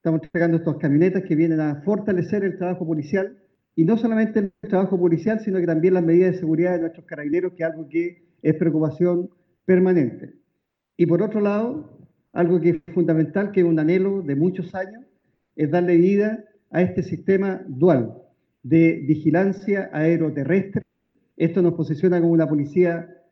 En la ceremonia, realizada en la Segunda Comisaría de Control de Orden Público de Pailahueque, se hizo una prueba del dron Camcopter S-100 que tuvo un costo de siete mil millones de pesos y que fue comprado a una empresa austriaca.
El general director de Carabineros, Marcelo Araya Zapata, junto con agradecer a entrega de estos equipos, dijo que con esto se cumple el anhelo de la institución que es fortalecer la vigilancia aéreo terrestre.